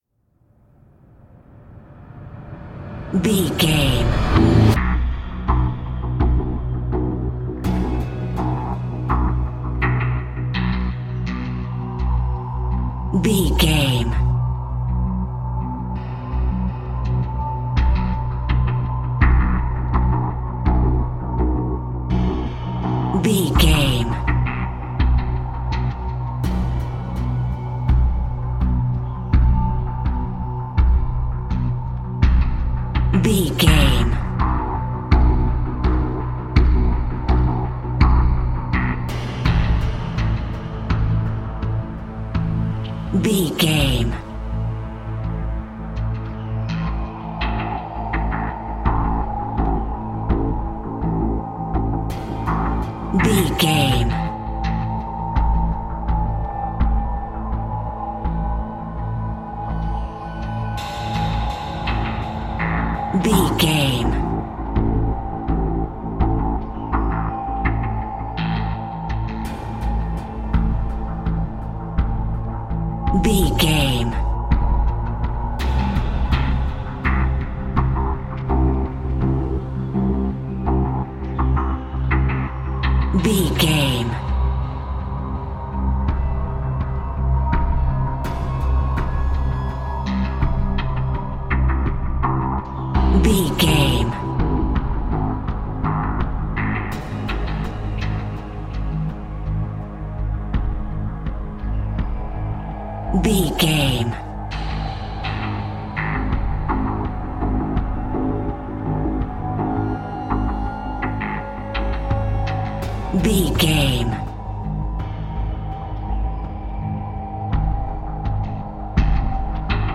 Thriller
Aeolian/Minor
synthesiser
drum machine